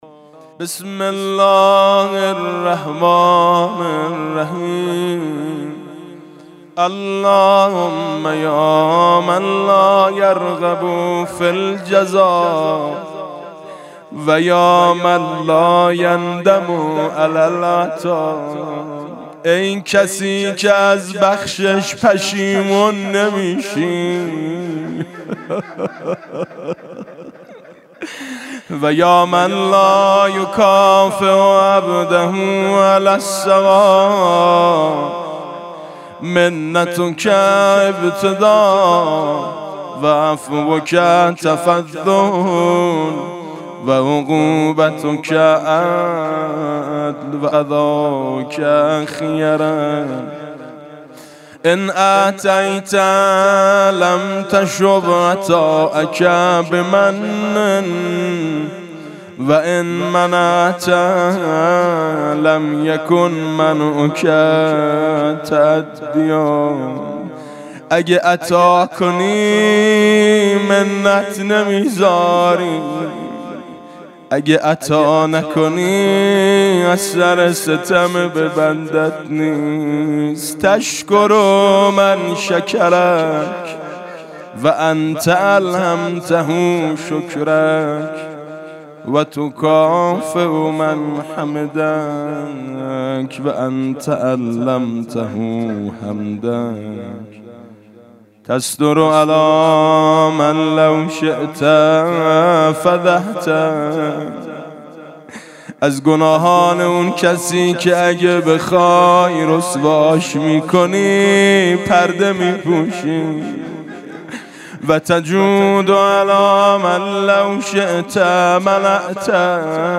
تهران- الکوثر: در آستانه به پایان رسیدن ماه عبادت و بندگی، دعای وداع با ماه مبارک رمضان از لسان مبارک امام زین العابدین(ع) با نوای میثم مطیعی را به صورت صوتی می شنوید.